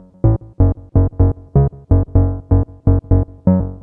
cch_bass_raver_125_F#m.wav